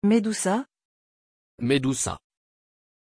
Pronunciation of Medusa
pronunciation-medusa-fr.mp3